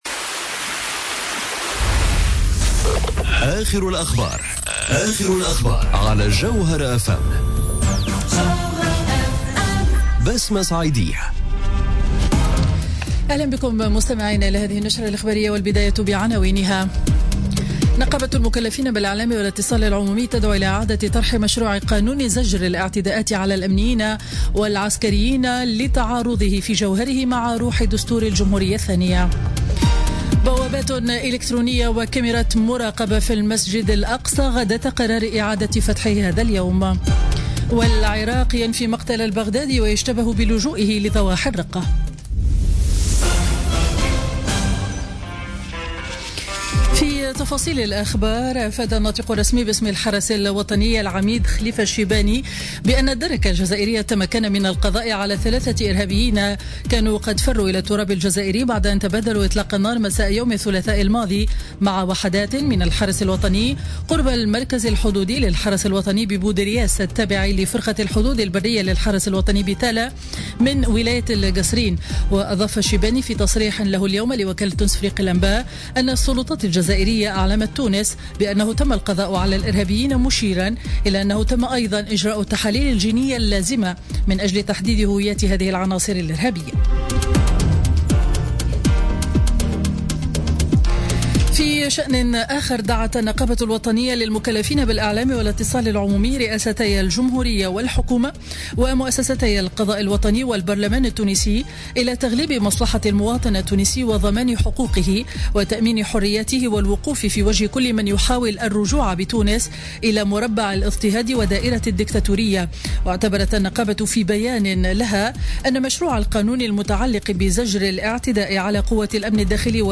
نشرة أخبار منتصف النهار ليوم الأحد 16 جويلية 2017